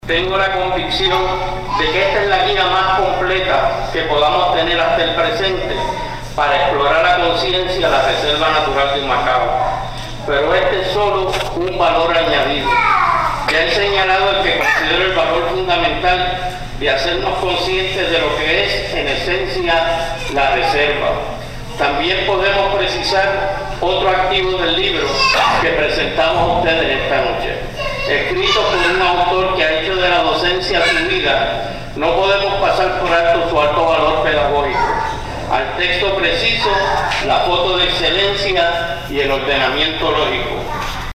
Exitosa presentación de La Guía de La Reserva Natural de Humacao en La Casona